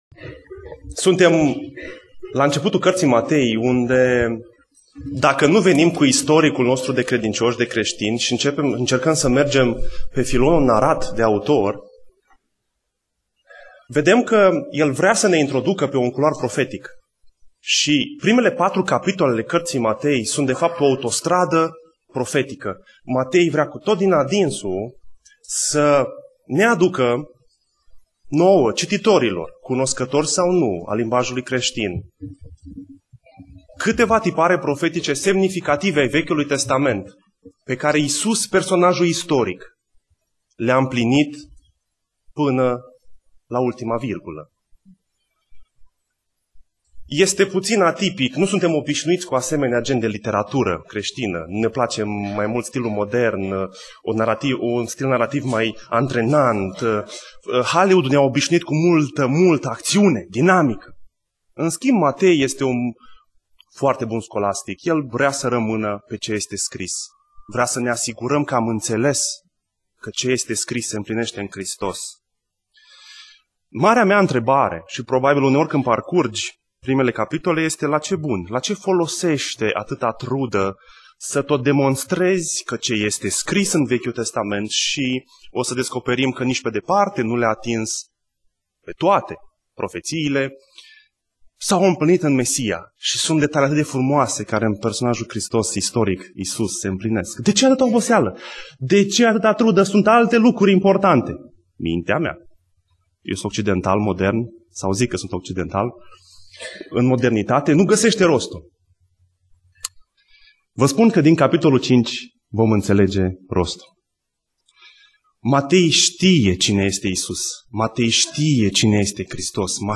Predica Exegeza - Matei 5